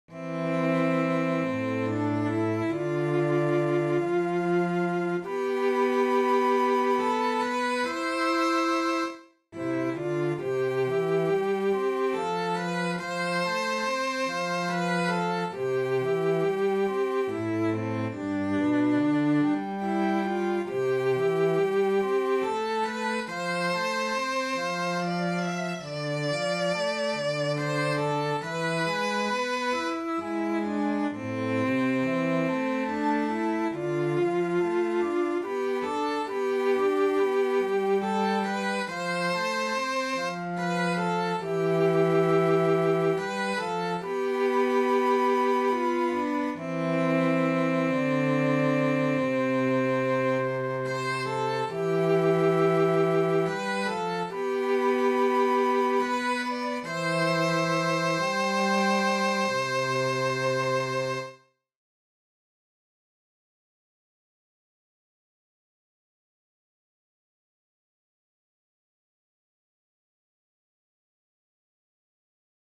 Viimeinen-lokki-sellot-ja-huilu.mp3